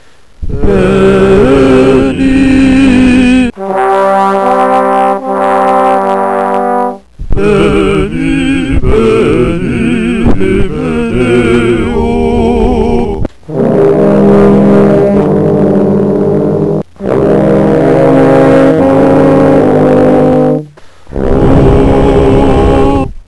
Wave files are sounds that you record with a microphone. The clicking noises in the background of some songs is my metronome.